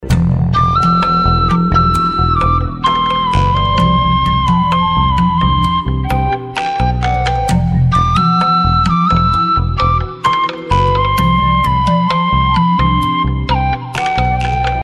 • Качество: 128, Stereo
Прикольная мелодия флейты на смс для вашего телефона